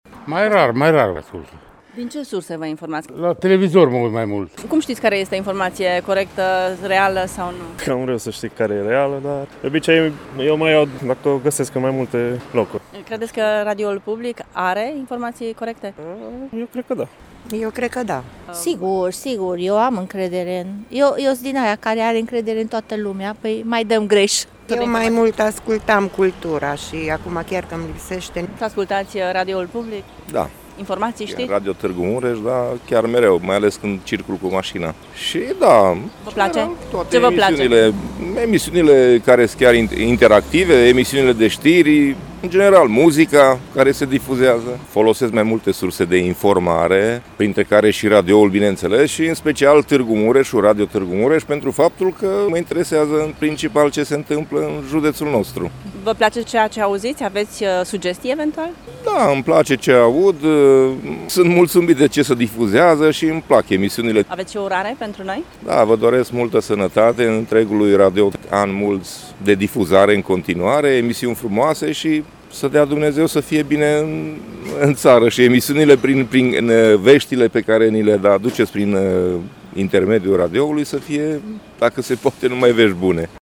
Târgumureșenii, indiferent de vârstă, recunosc valoarea și relevanța Radioului public între toate celelalte surse media.